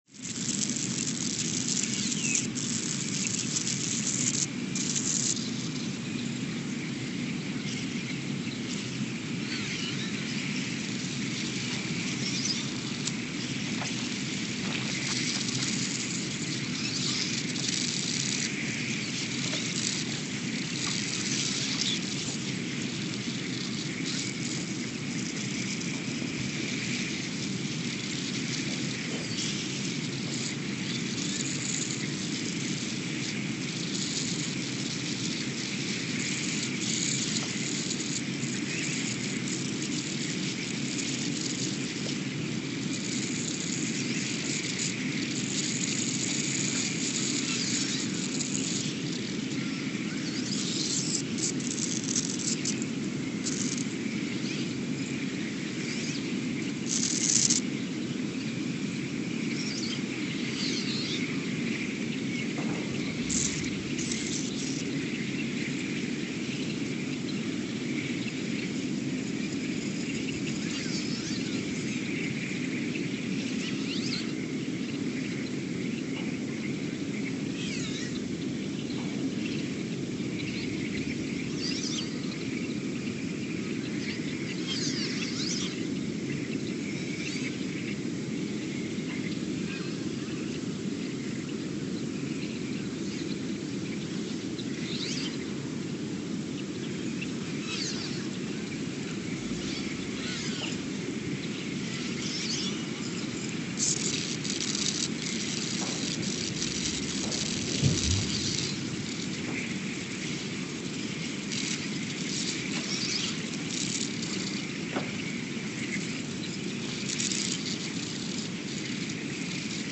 The Earthsound Project: Ulaanbaatar, Mongolia (seismic) archived on July 3, 2024
No events.
Station : ULN (network: IRIS/USGS) at Ulaanbaatar, Mongolia
Sensor : STS-1V/VBB
Speedup : ×900 (transposed up about 10 octaves)
Loop duration (audio) : 03:12 (stereo)